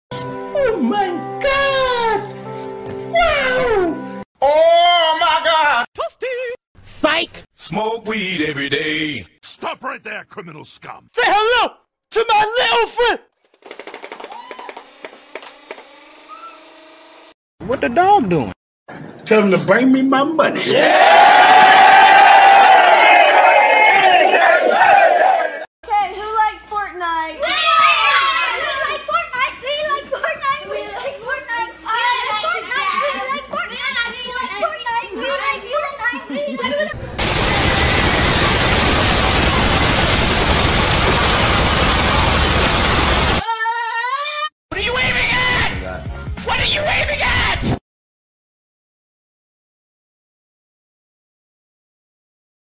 Editing Just Got Better: 10+ sound effects free download
These 10+ FREE sound effects will instantly improve your edits — perfect for memes, transitions, intros, reactions, and cinematic vibes.